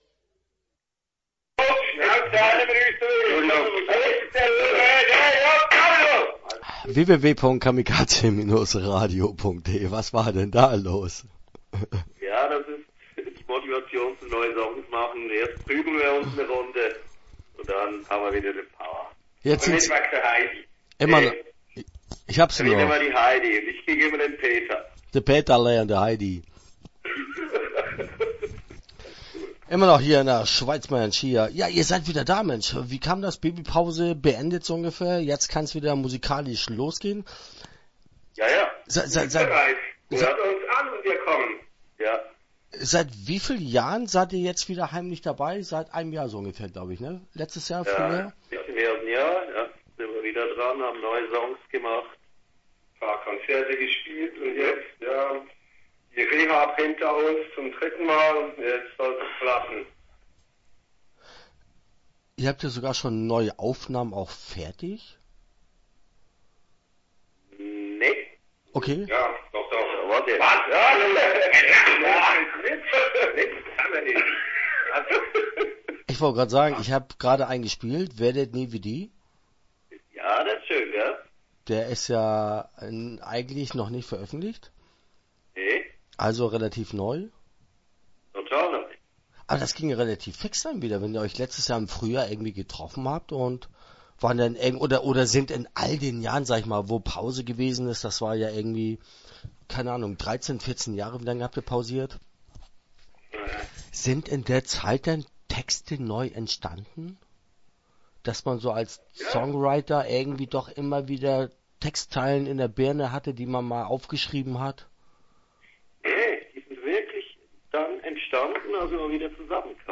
Die Cadizier - Interview Teil 1 (10:55)